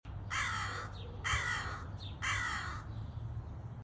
Corvus Corax or common raven sound effects free download
Corvus Corax or common raven croaking.